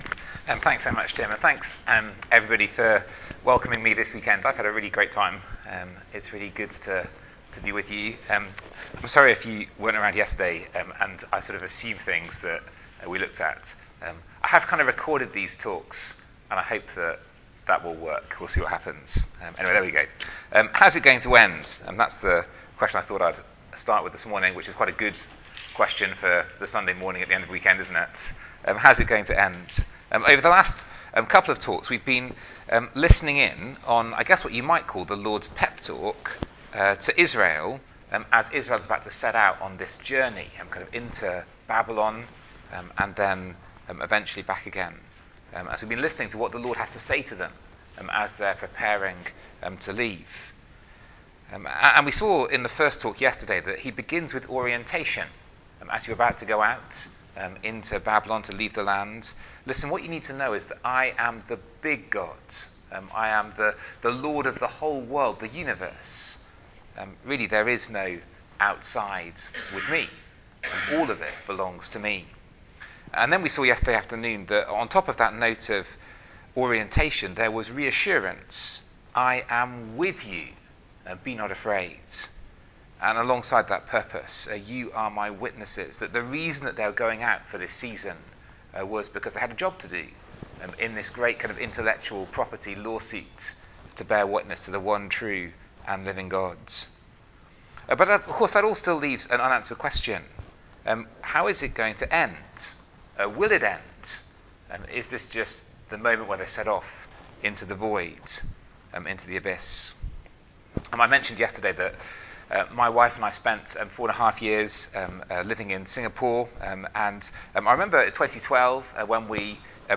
Talk-3-how-will-the-time-on-the-outside-end.mp3